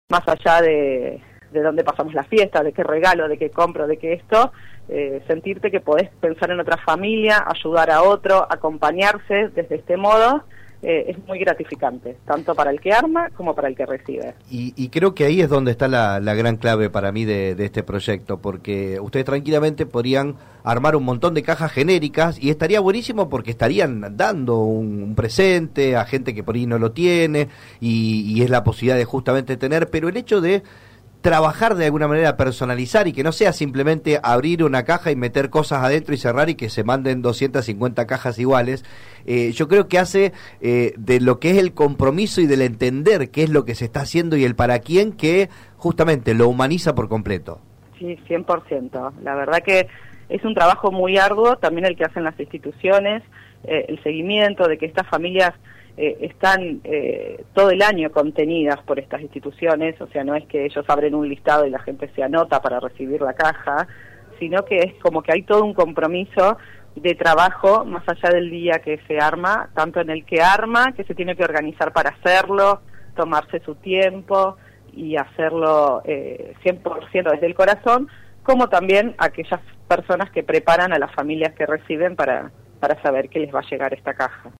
en diálogo